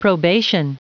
Prononciation du mot probation en anglais (fichier audio)
Prononciation du mot : probation